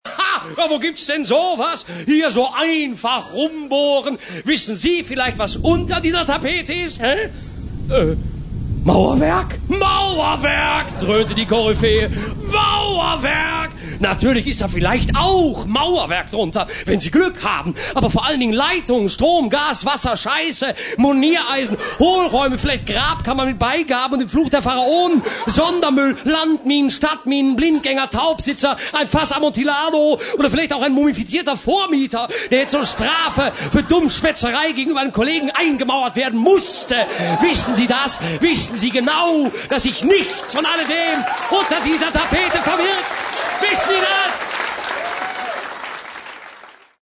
(Literarisches Kabarett)